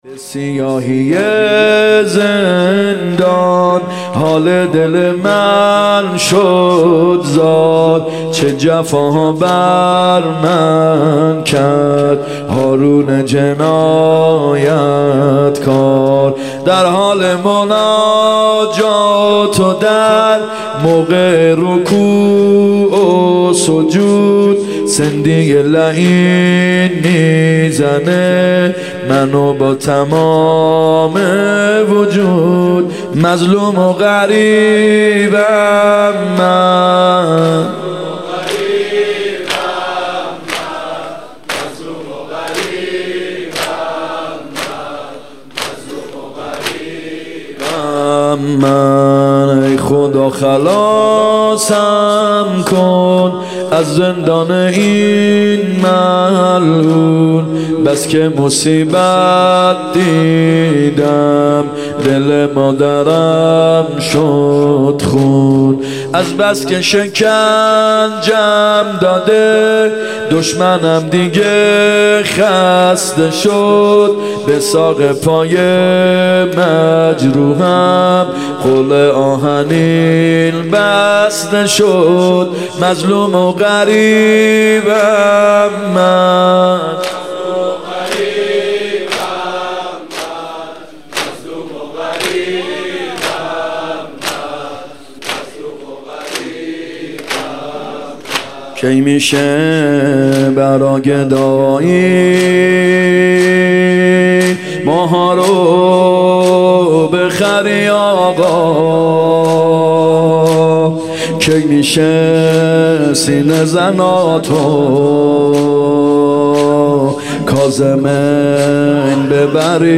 شهادت امام کاظم ع 96 زمینه ( یه سیاهی زندان
شهادت امام کاظم ع (هیات یا مهدی عج)